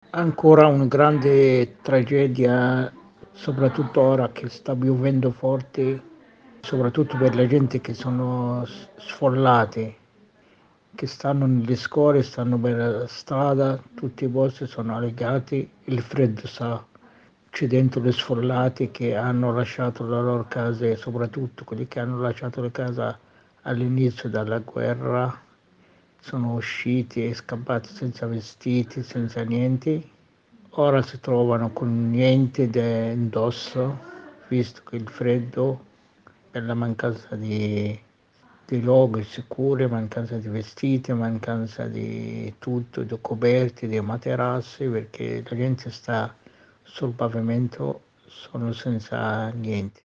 E questo complica ulteriormente le cose per le centinaia di migliaia di profughi interni. Il racconto, dal sud di Gaza, di un cittadino palestinese.